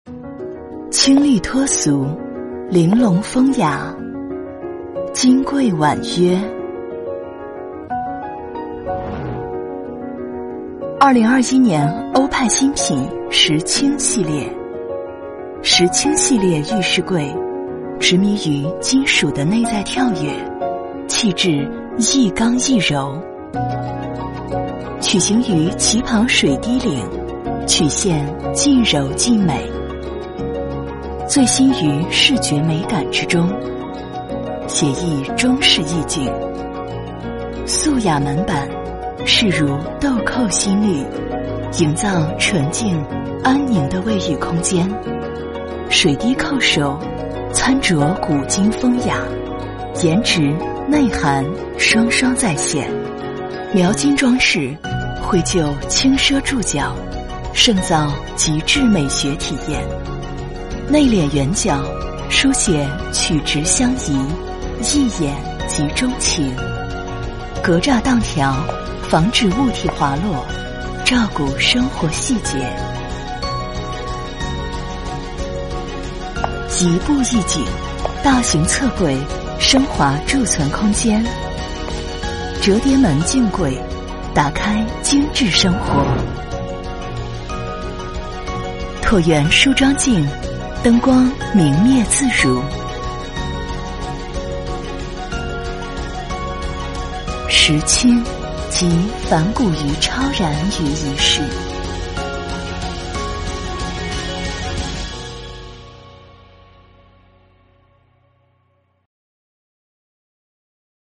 女267-广告-欧派--拾青系列.mp3